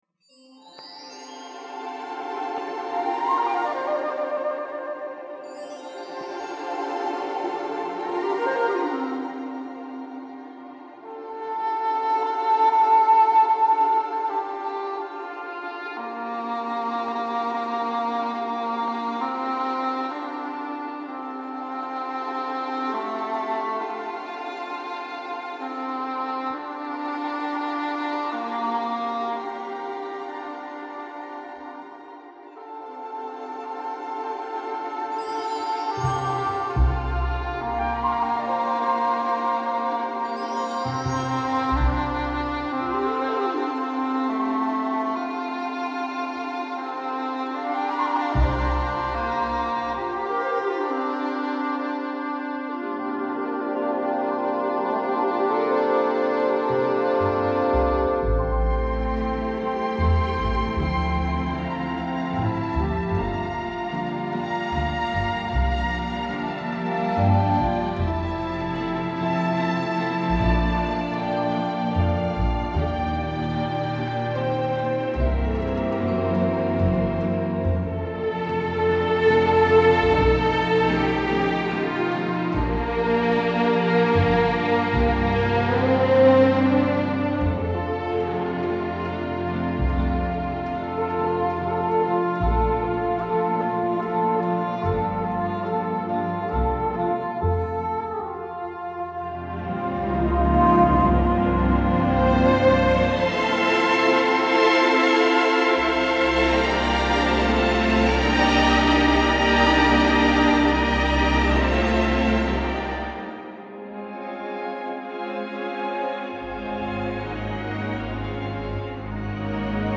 Genre:Easy Listenimg